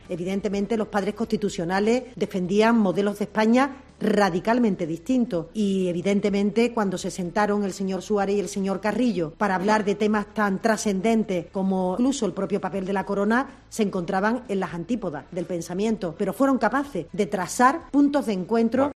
Declaraciones de Montero tras el Consejo de Ministros